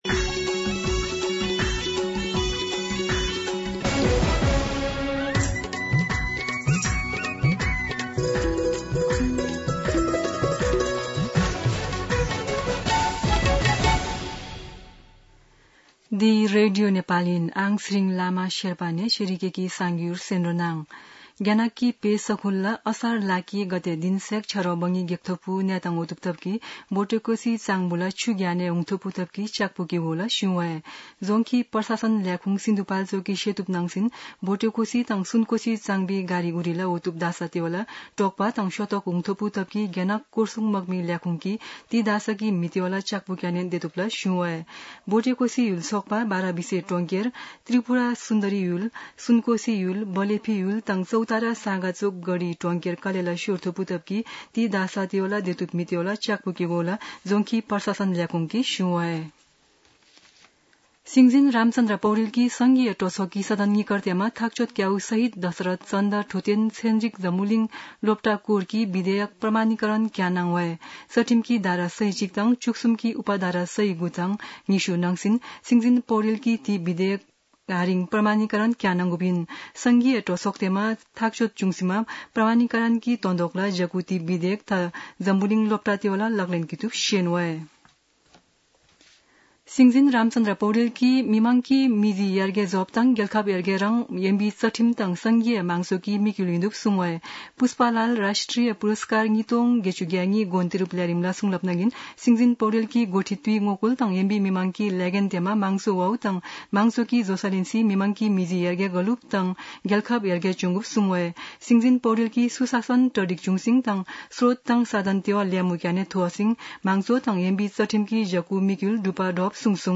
शेर्पा भाषाको समाचार : ४ असार , २०८२
Sherpa-News-03-4.mp3